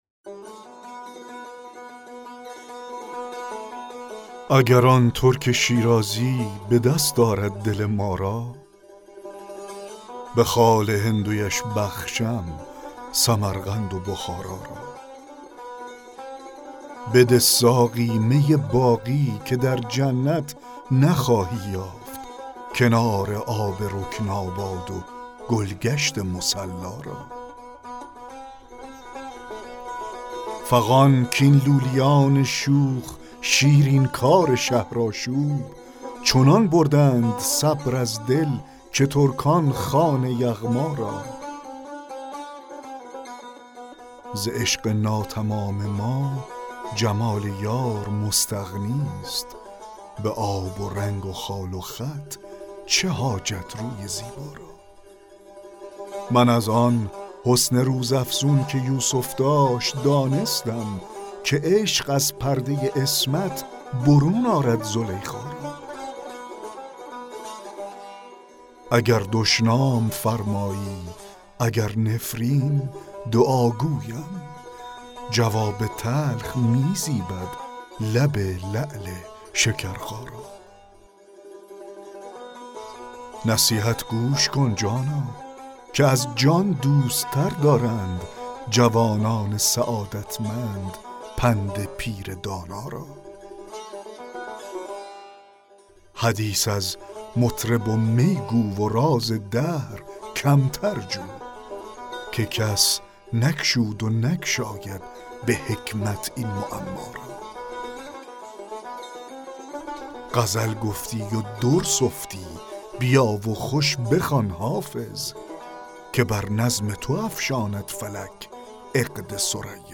دکلمه غزل 3 حافظ